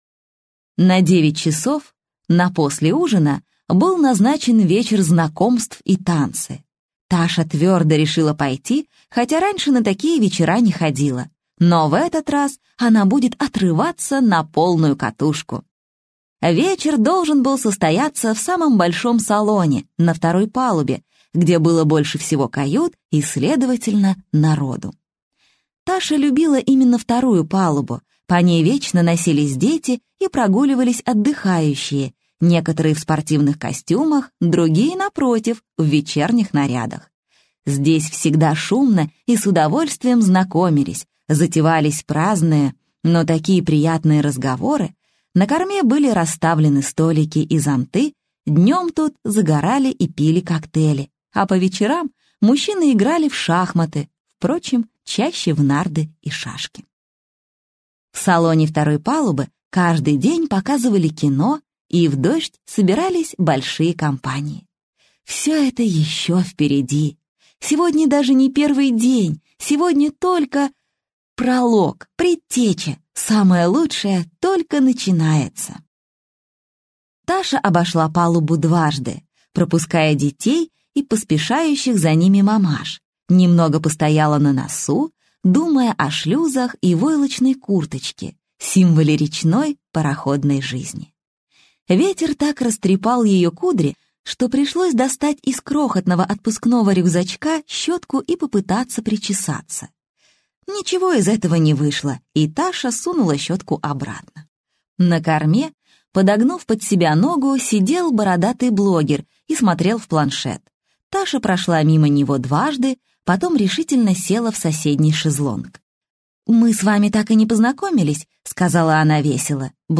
Аудиокнига Детектив на краю лета | Библиотека аудиокниг